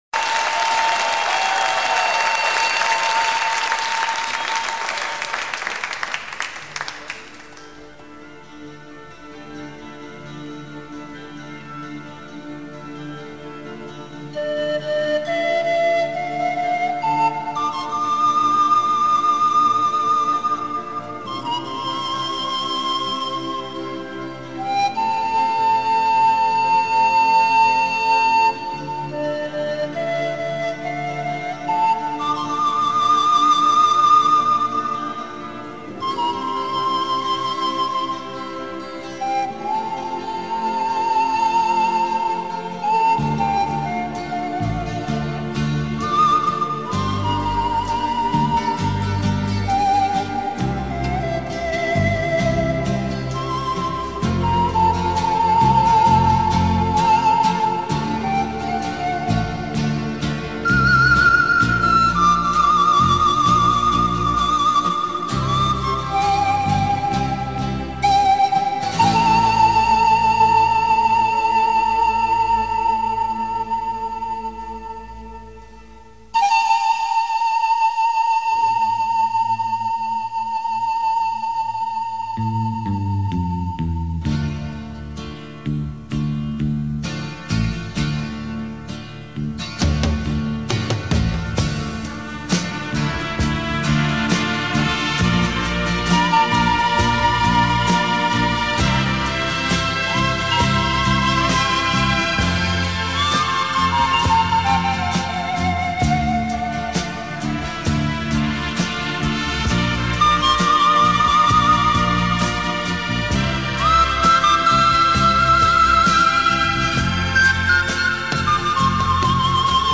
pan flute music
The pan flute's haunting and beautiful sounds are played by blowing horizontally across an open end of a tube, against the sharp inner edge.